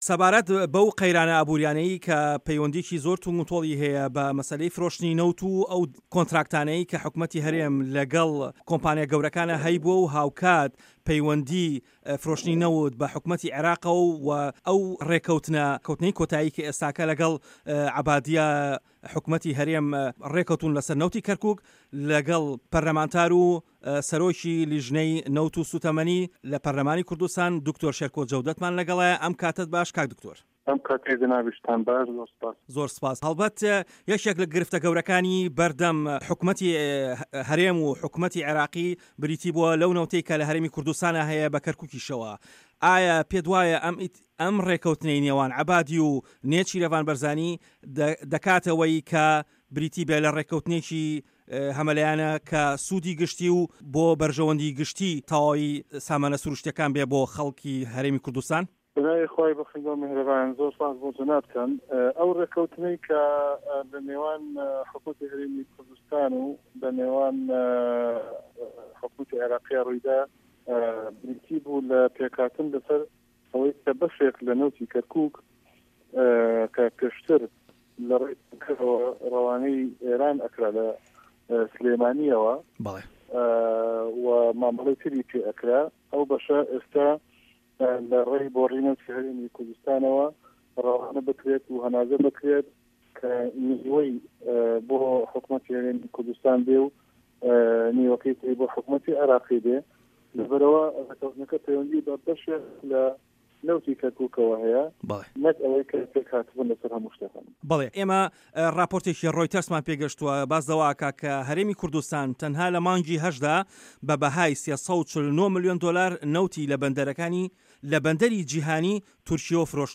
وتووێژ لەگەڵ شێرکۆ جەودەت